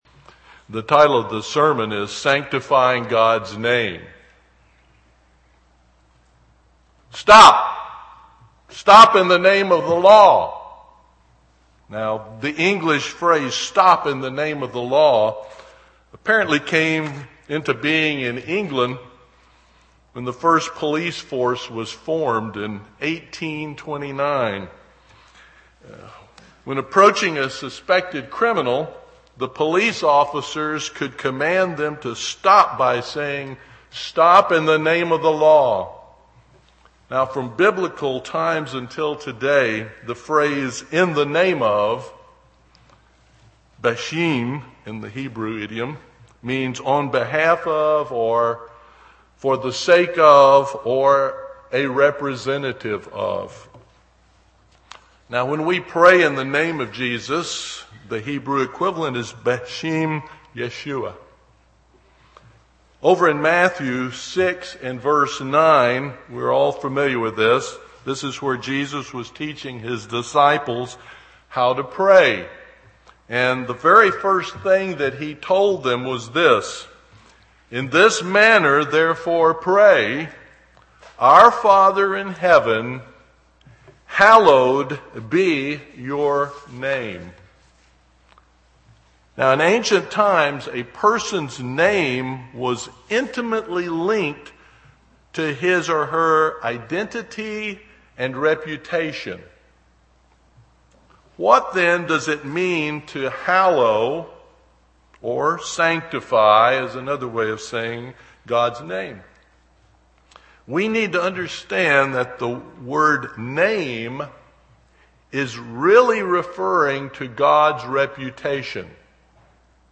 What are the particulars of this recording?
Given in East Texas